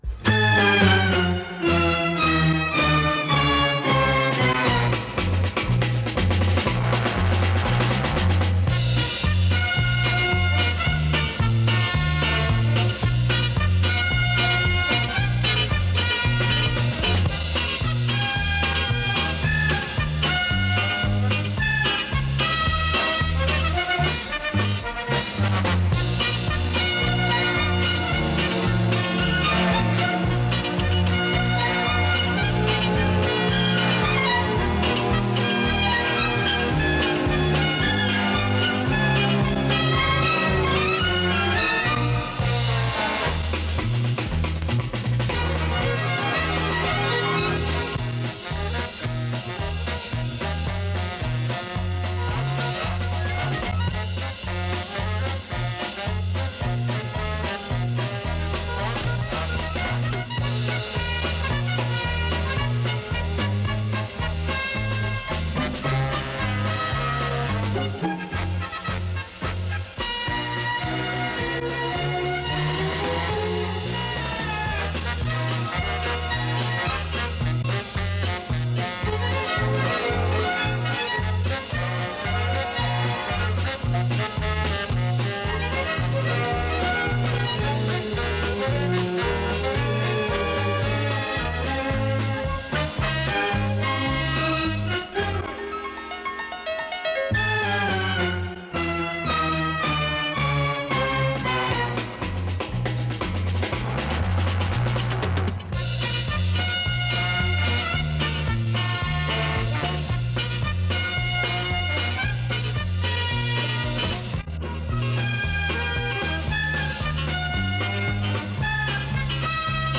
Любителям инструментальной музыки несколько эстрадных пьес с катушки.